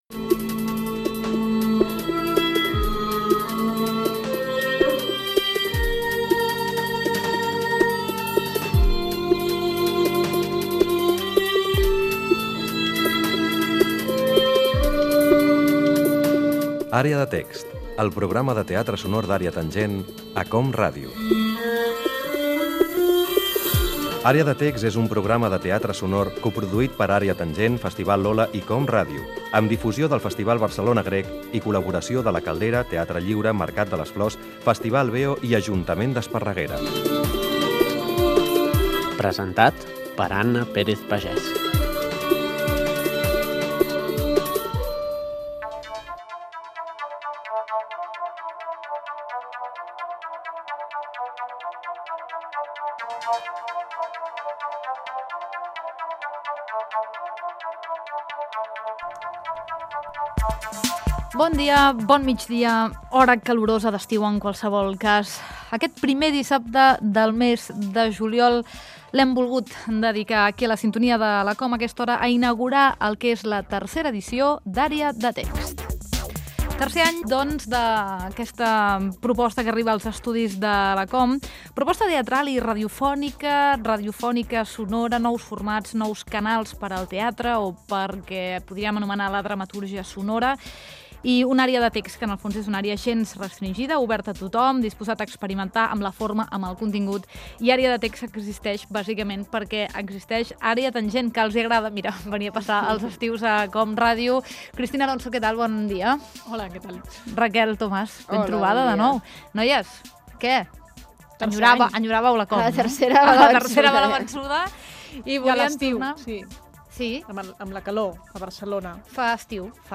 Careta del programa, inici de la tercera temporada del programa dedicat a les noves dramatúrgies i els nous formats teatrals radiofònics. Textos teatrals inèdits escrits i dissenyats acústicament per ser posats en antena.
Tercera temporada del programa de teatre sonor.